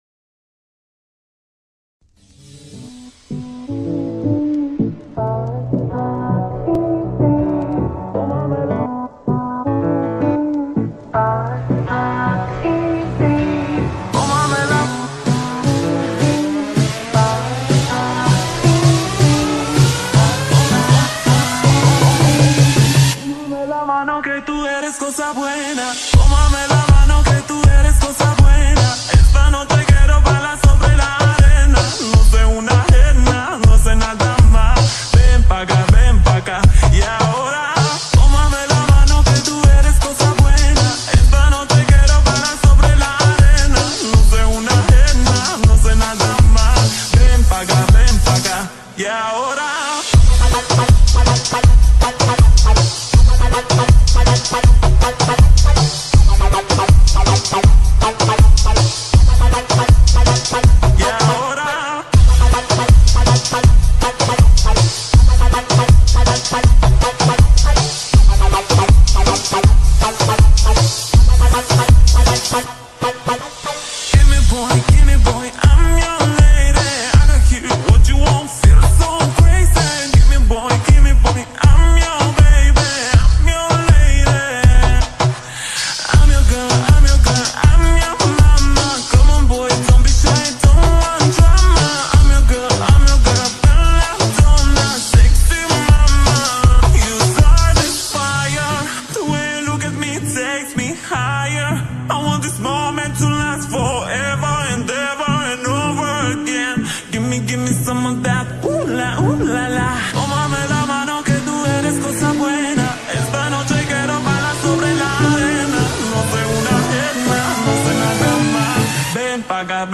SUPER SLOWED REMEX MUSIC SONG